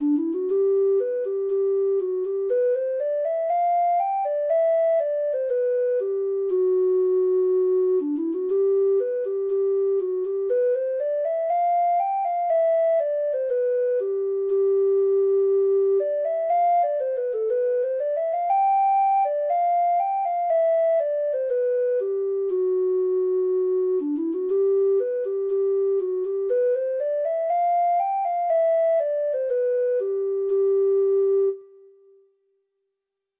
Traditional Music of unknown author.
Irish